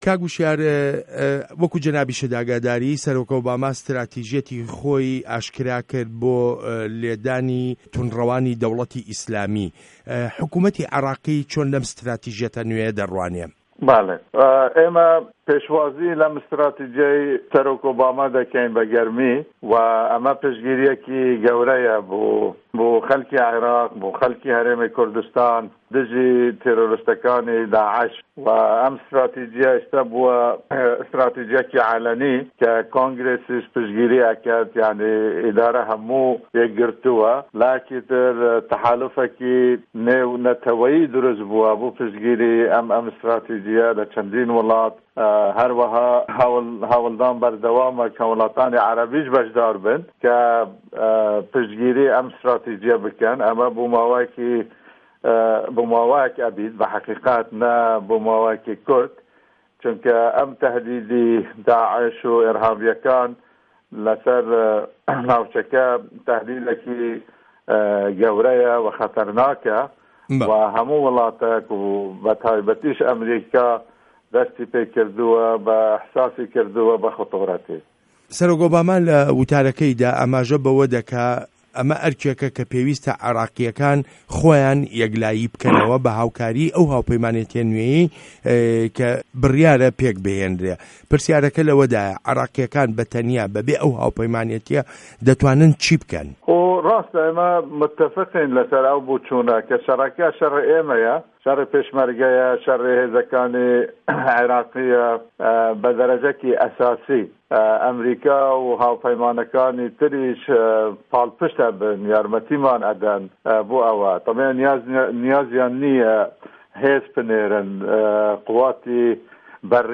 وتووێژ له‌گه‌ڵ هوشیار زێباری